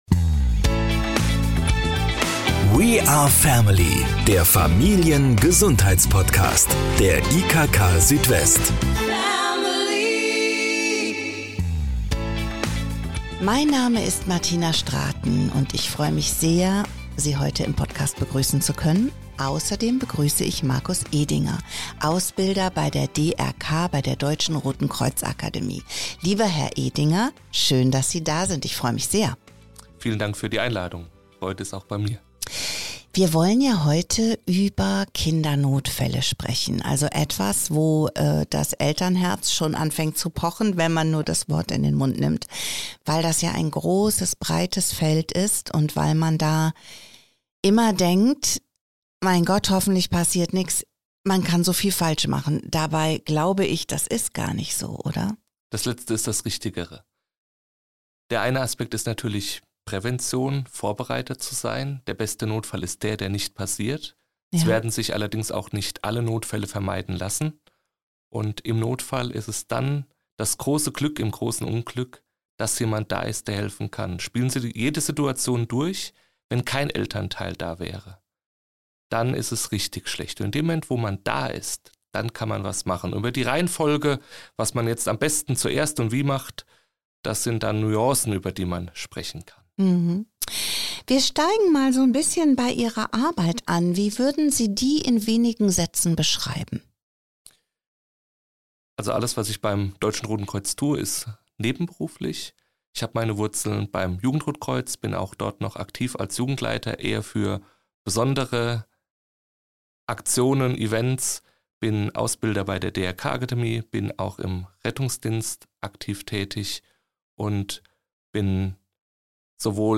Er ist Ausbilder bei der DRK-Akademie Kaiserslautern. Es geht um die wichtigsten Handgriffe, den berühmten kühlen Kopf und das nötige Selbstvertrauen.